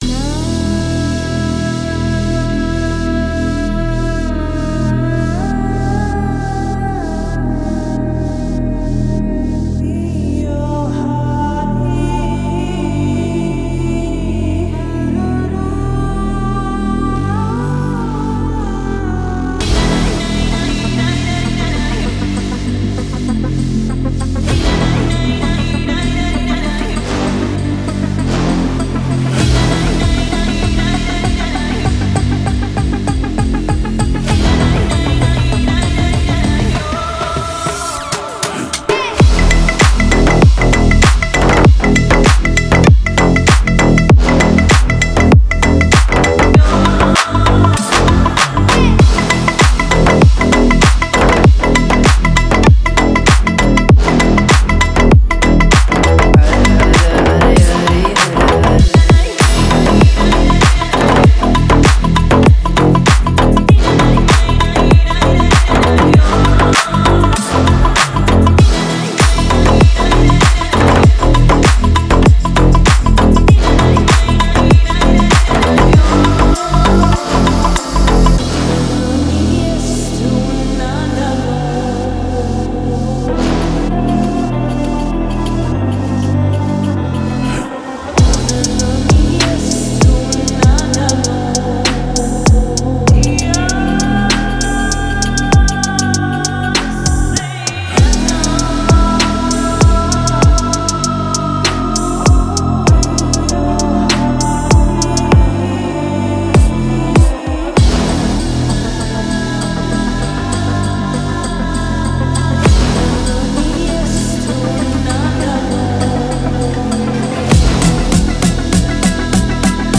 Категория: Клубная музыка
клубные треки